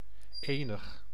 Ääntäminen
IPA: /ˈeːnəx/